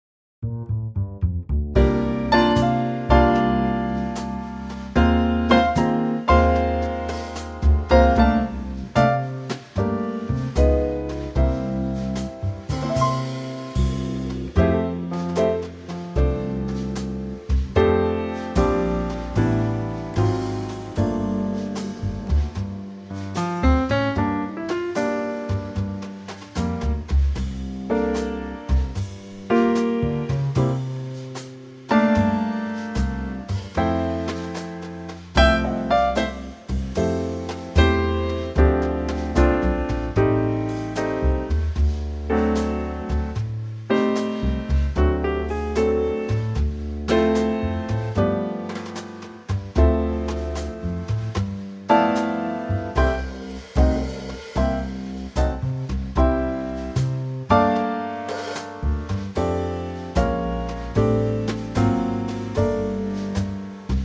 key - F - vocal range - G# to Bb
Superb Trio arrangement of the classic standard
-Unique Backing Track Downloads